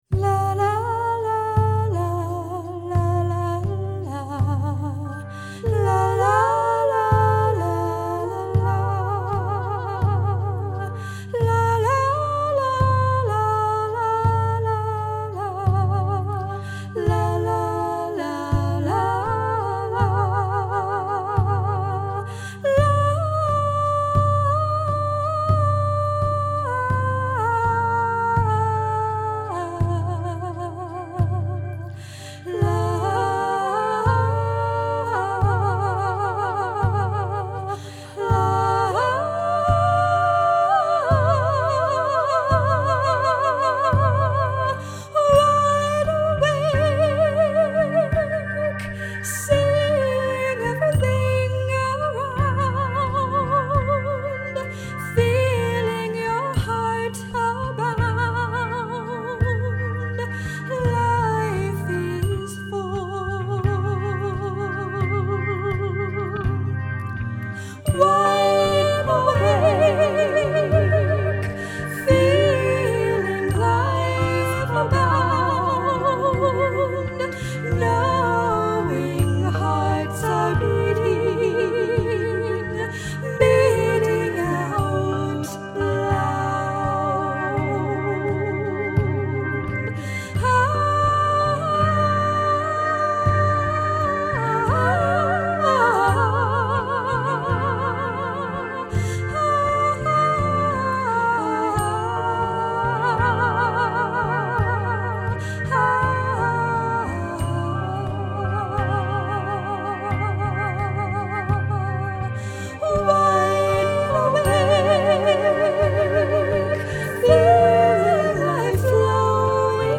Tingshas
Organ, electric guitar and bass, soundscape
a joyous melody